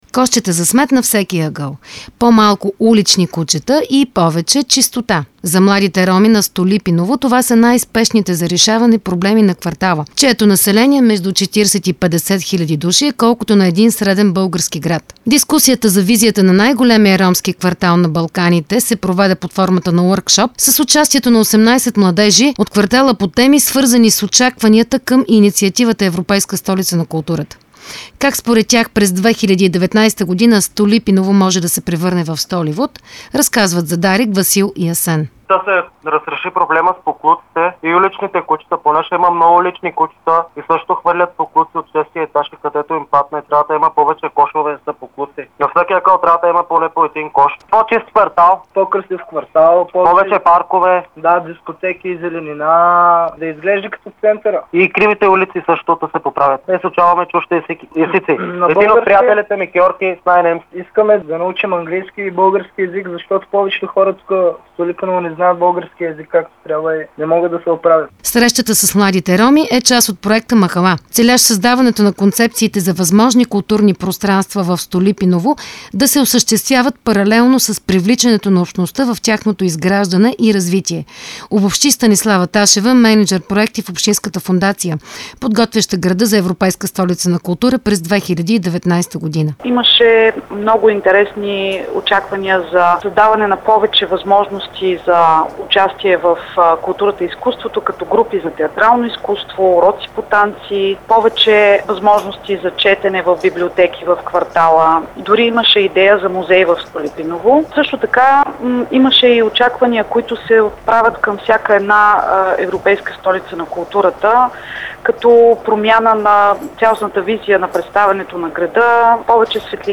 Reportaz-Stolipinovo-3-37.mp3